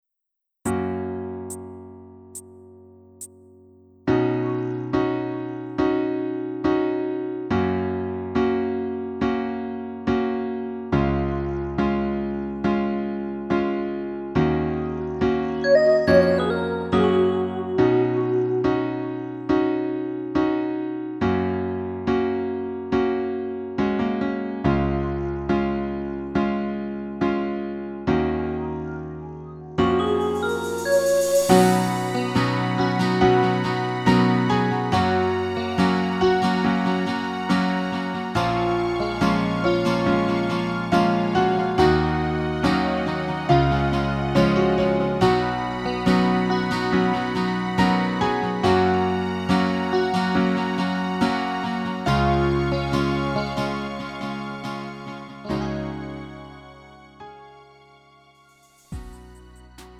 음정 원키 4:01
장르 가요 구분 Lite MR
Lite MR은 저렴한 가격에 간단한 연습이나 취미용으로 활용할 수 있는 가벼운 반주입니다.